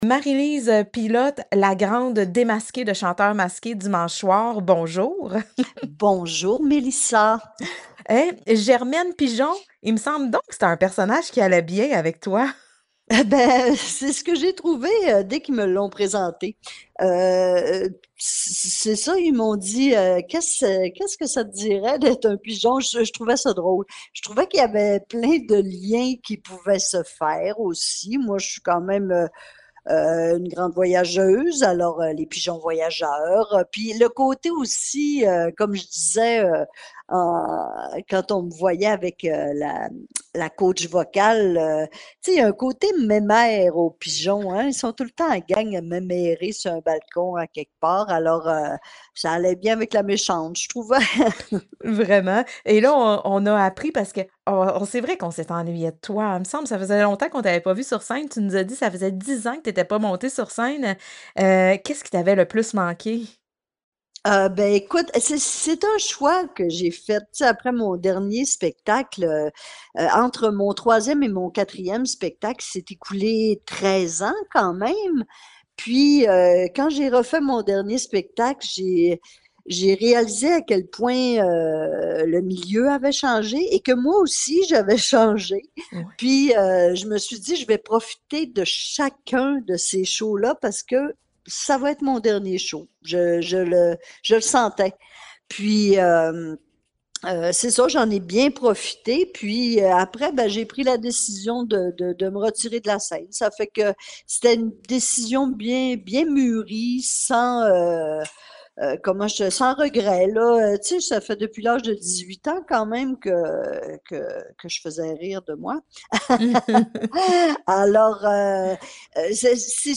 Marie-Lise-Pilote-entrevue.mp3